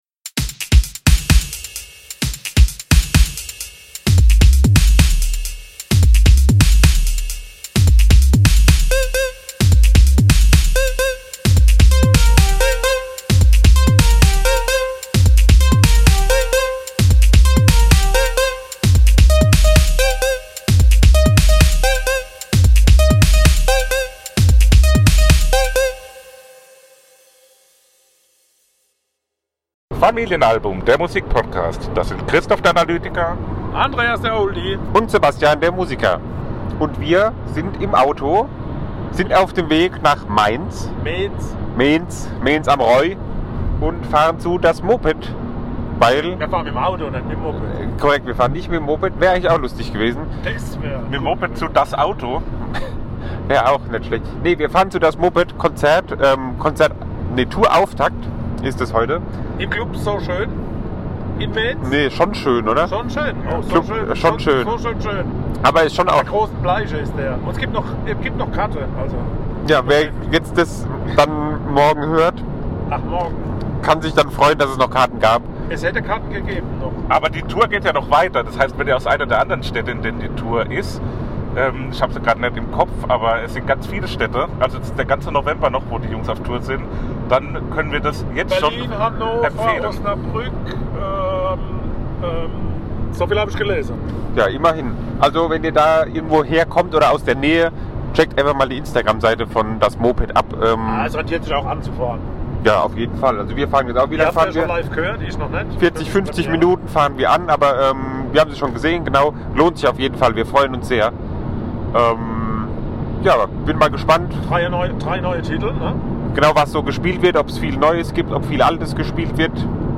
Im Club "Schon Schön" spielt Das Moped und wir berichten vor und nach dem Konzert über unsere Erwartungen und ob diese erfüllt wurden!